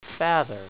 The A is pronounced like apple and the R strongly enunciated producing (Mpg) (aifc)
(au) F-aaaa-th-rrrrrrr.
I repeat the word in common english and then in elizabethan. These were taken off the built-in mike on a powerbook in 1994, so excuse the quality!